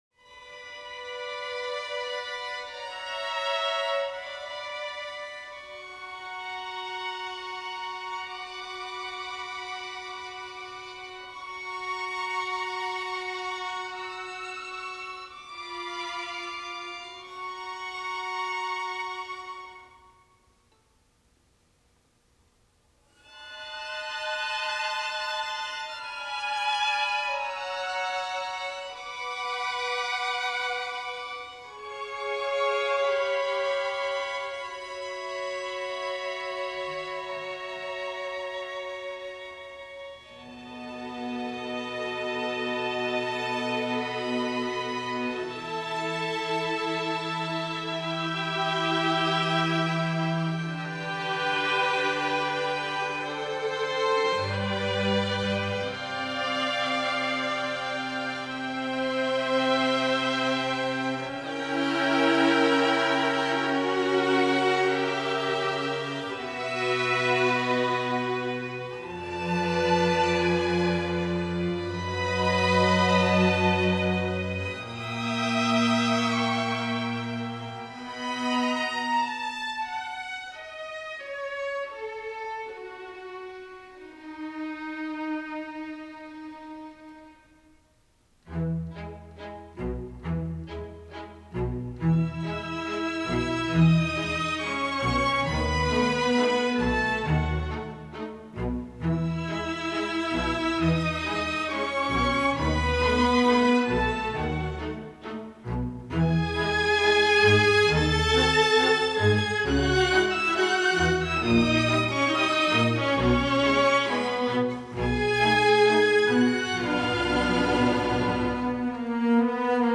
gut-wrenchingly powerful piece
String Orchestra